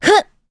Hilda-Vox_Jump.wav